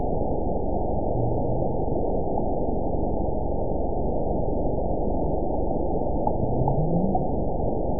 event 912165 date 03/19/22 time 15:53:54 GMT (3 years, 2 months ago) score 9.65 location TSS-AB03 detected by nrw target species NRW annotations +NRW Spectrogram: Frequency (kHz) vs. Time (s) audio not available .wav